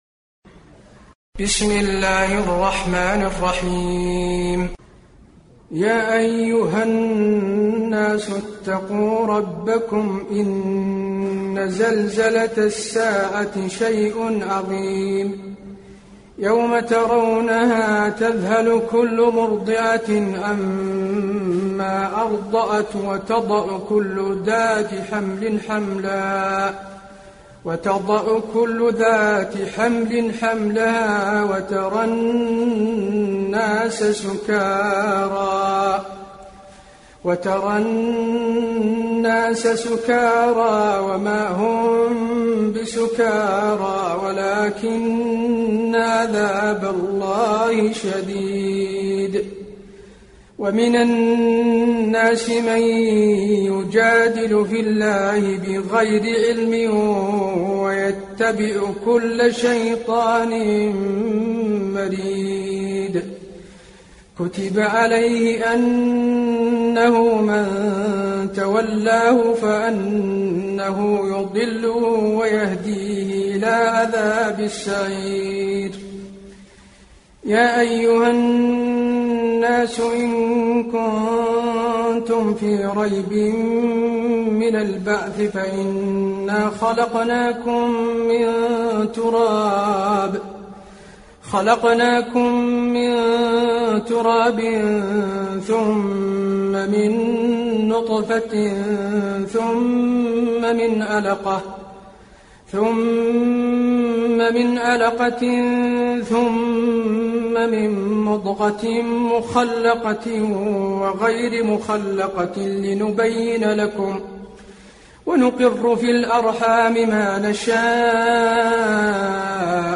المكان: المسجد النبوي الحج